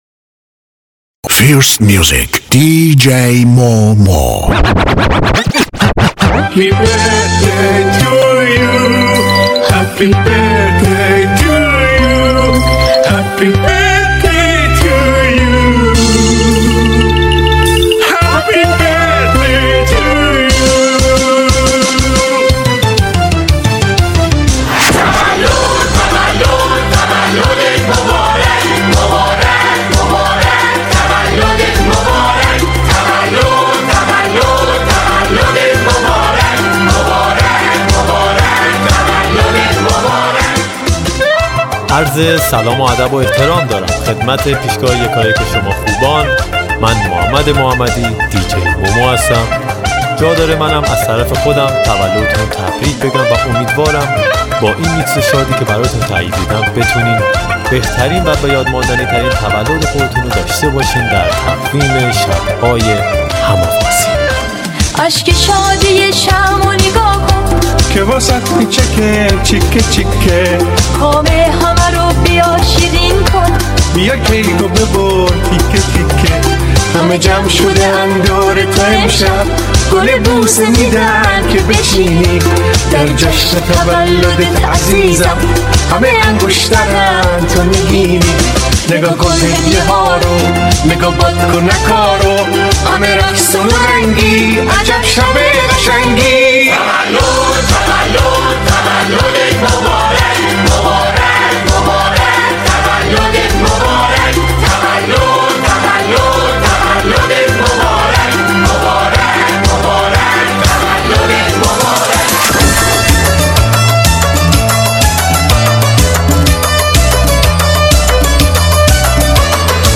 دانلود آهنگ شاد تولد برای رقص بزرگسالان
دانلود ریمیکس شاد یک ساعته مخصوص تولد